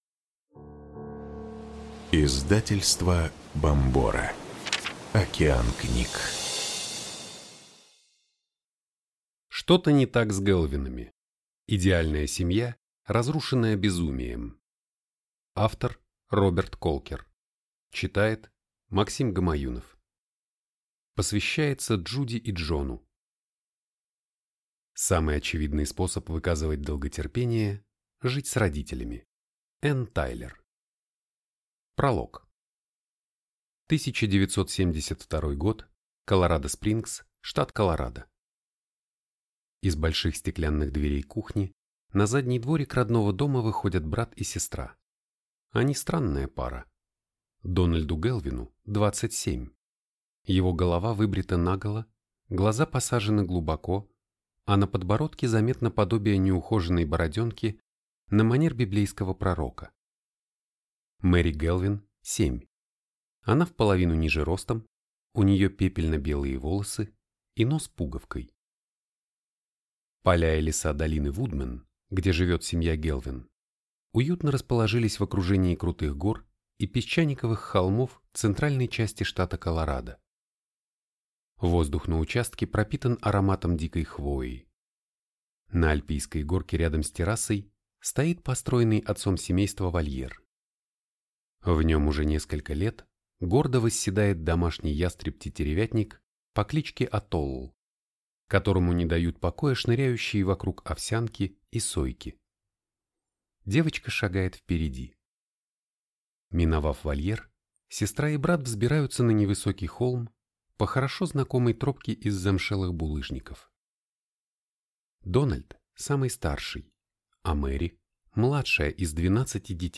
Аудиокнига Что-то не так с Гэлвинами. Идеальная семья, разрушенная безумием | Библиотека аудиокниг